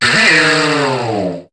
Cri d' Écaïd dans Pokémon HOME .